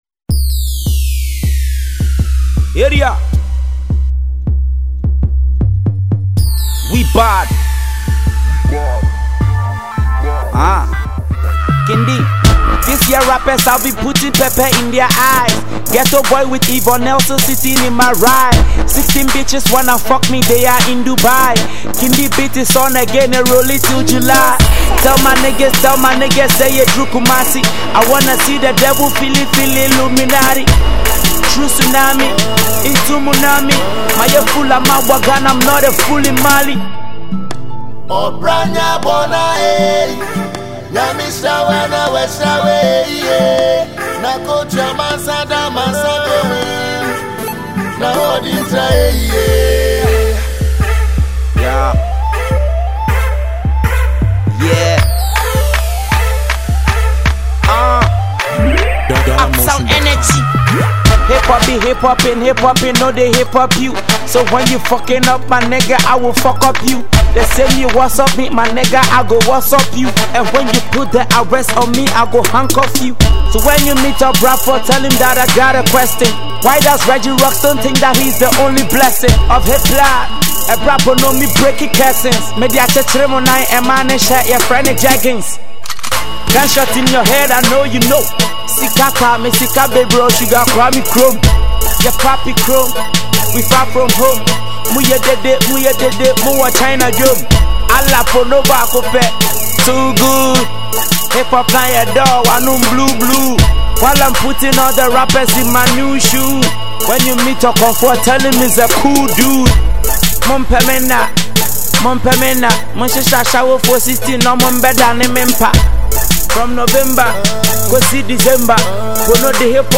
Ghanaian Lyricist
a Straight banger